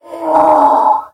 donkey
angry1.mp3